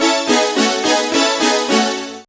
Synth Lick 49-02.wav